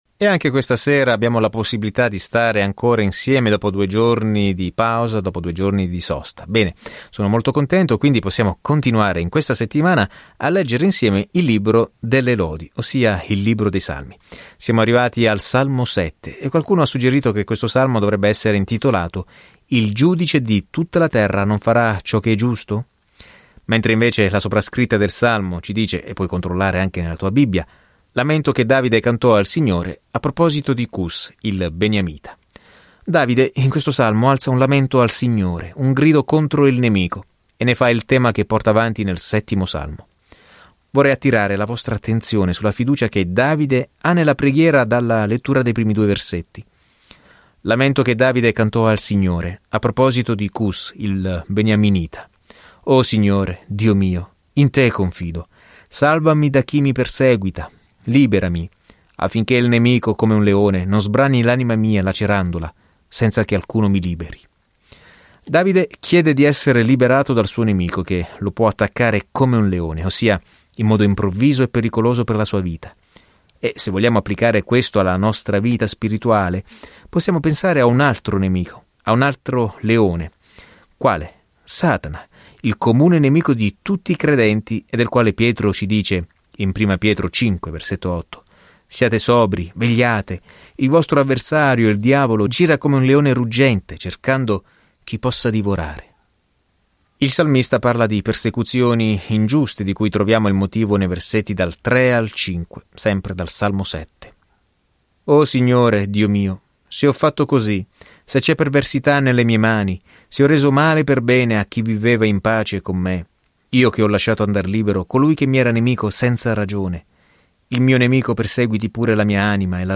Viaggia ogni giorno attraverso i Salmi mentre ascolti lo studio audio e leggi versetti selezionati della parola di Dio.